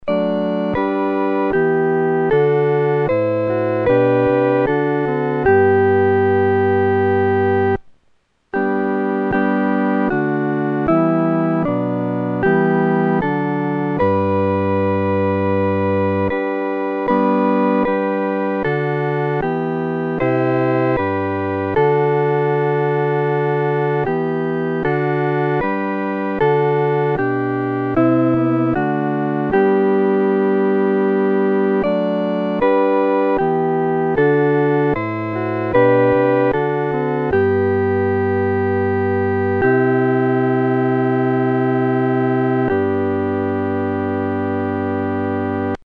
合奏（四声部）